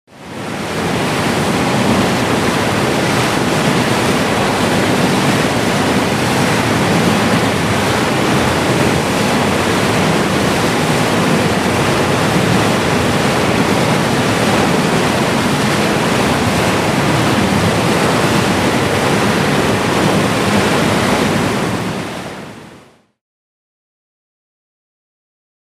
Huge Water Gush From Broken Fire Hydrant